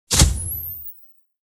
Photo-flash-sound-effect.mp3